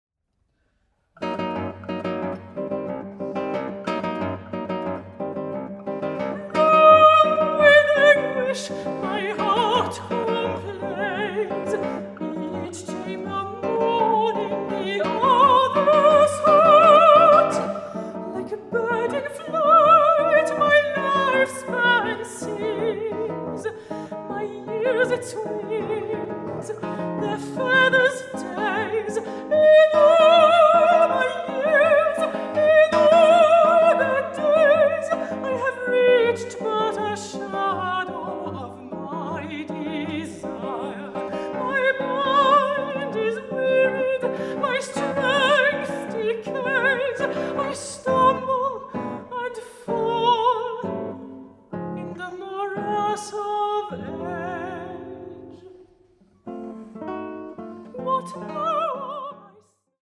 Guitar
Vocals